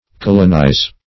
Kaolinize \Ka"o*lin*ize\